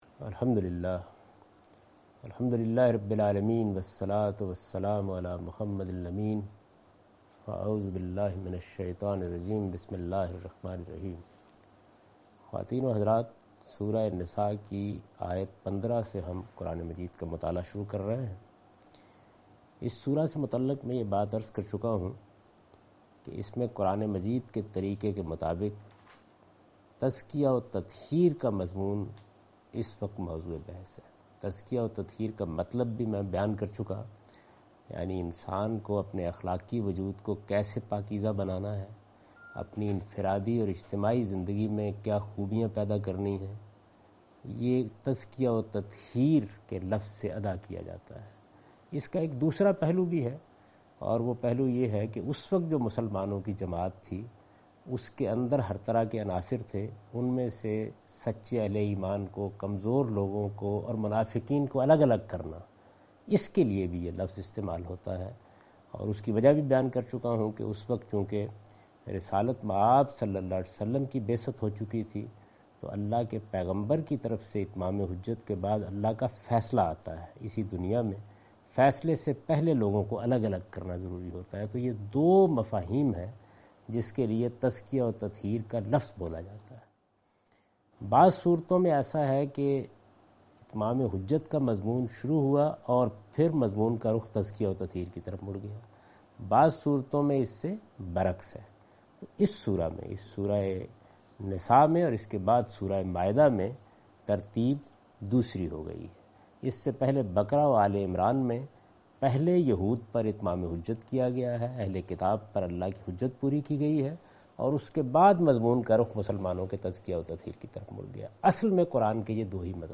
Javed Ahmad Ghamidi > Videos > درس تفسیر قرآن " البیان " - سوره النسا ( 15 - 18 )